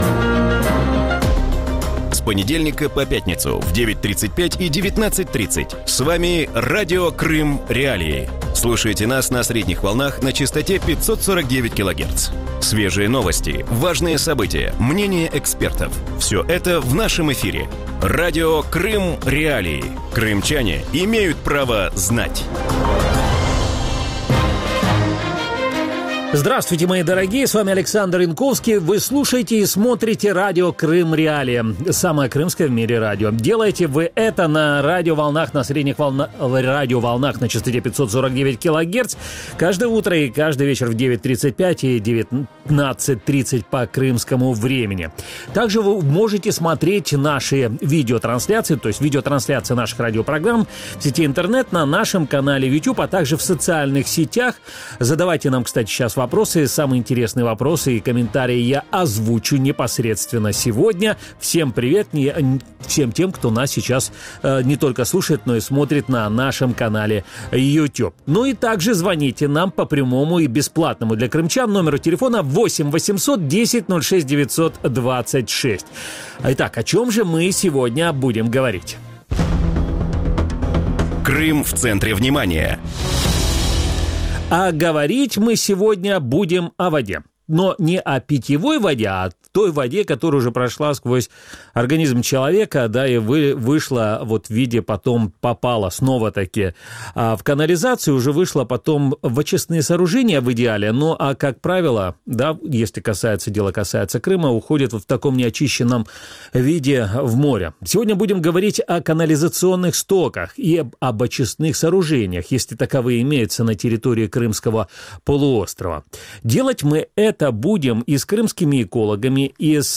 В вечернем эфире Радио Крым.Реалии говорят о проблеме канализационных стоков и изношенности очистных сооружений в Крыму. Насколько серьезна проблема сточных вод на полуострове, почему ее не могут решить крымские власти и смогут ли улучшить экологию частные инвесторы?